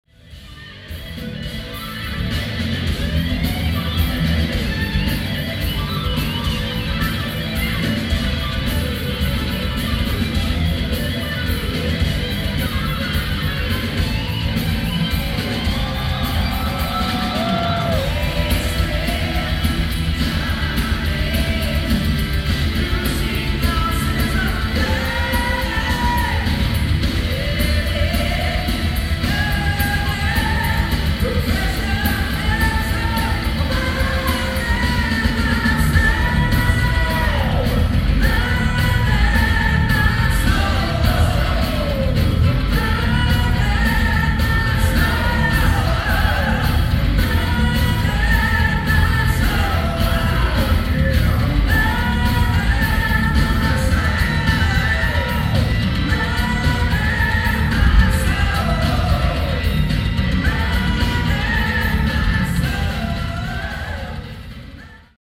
Source: Audience (Master DAT)
Venue: Beacon Theater
Location: New York City, NY
Equipment List: Sony PCM-M1, Soundman OKM II RKS mics
A good audience recording.